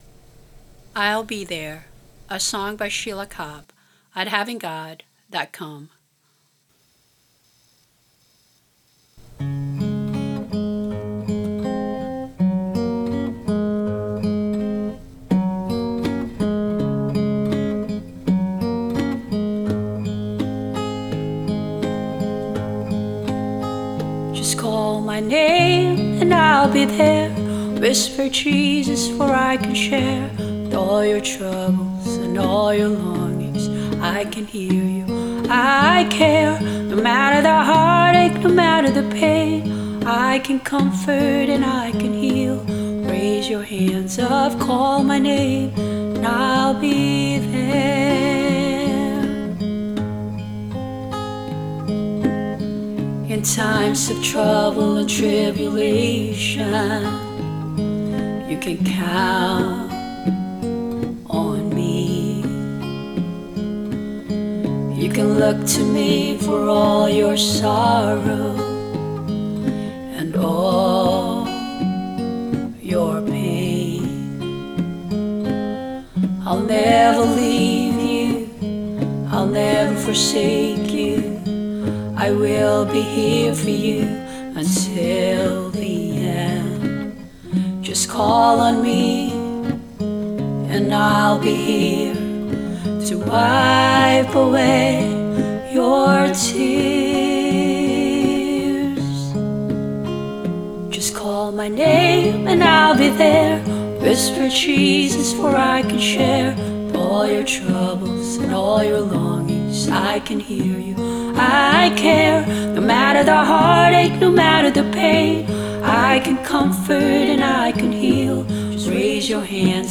Voices, guitar, and bongos